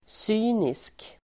Ladda ner uttalet
Uttal: [s'y:nisk]